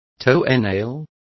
Complete with pronunciation of the translation of toenail.